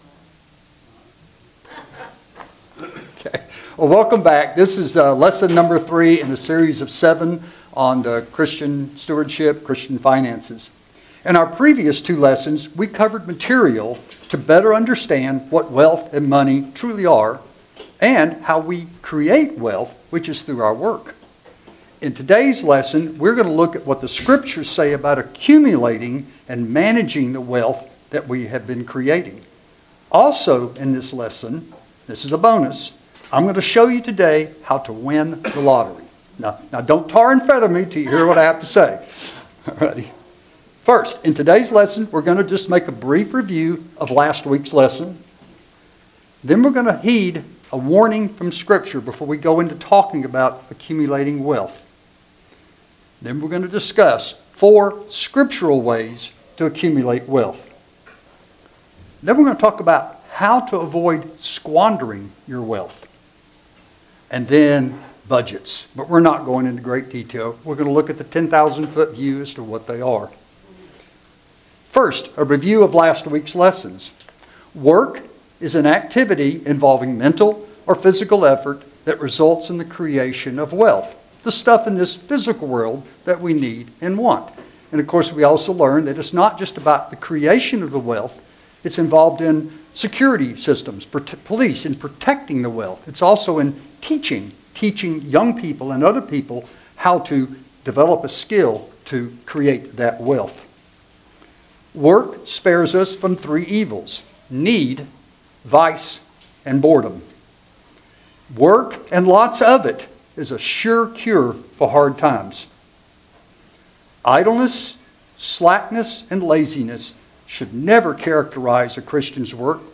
Service Type: Sunday School Handout